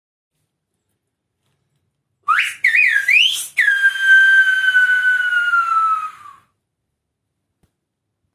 És una expressió que també s’entén com a comiat, perquè el xiulador rares vegades xiula “¡adiós!”  o “¡hasta la vista!